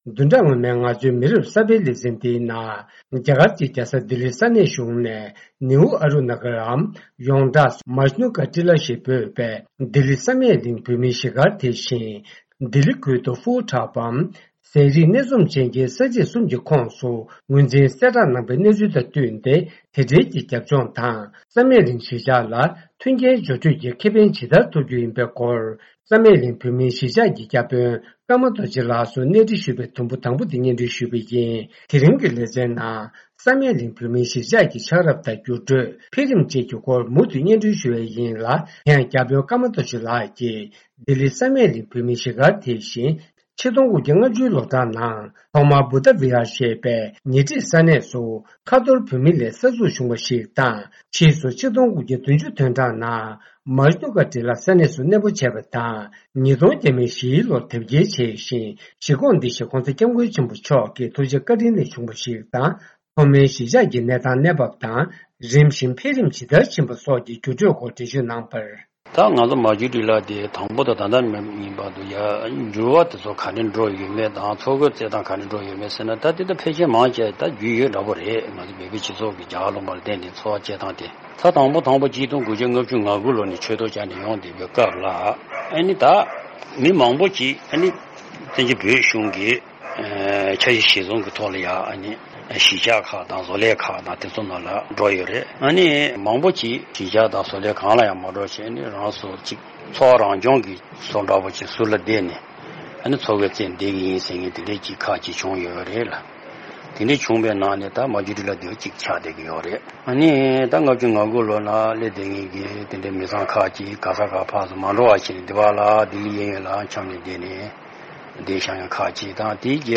བཅར་འདྲི་ཞུས་པའི་དུམ་བུ་དང་པོ་དེ་གསན་རོགས་ཞུ།